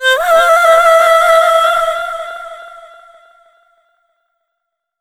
SCREAM1   -L.wav